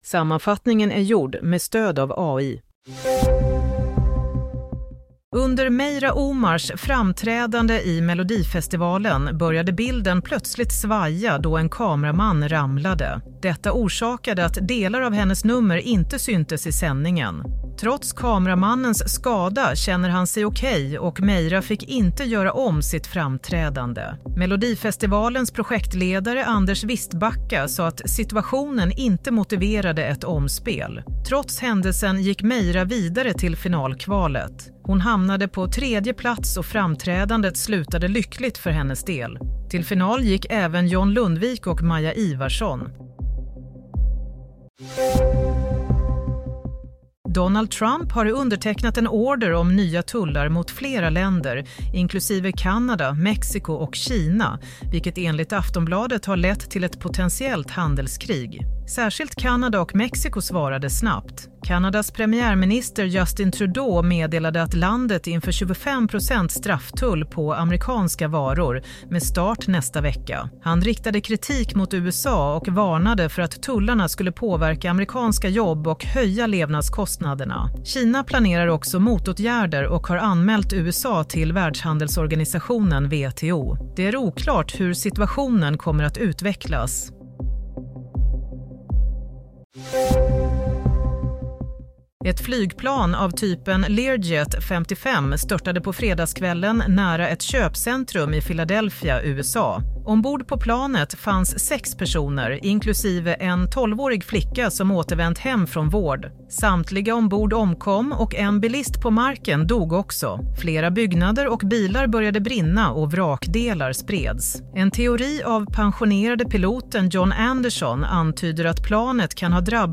Nyhetssammanfattning – 2 februari 07:00